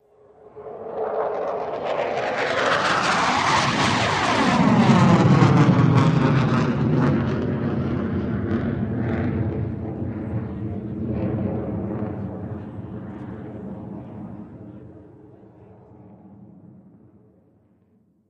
F-16 Fighting Falcon
F-16 By Medium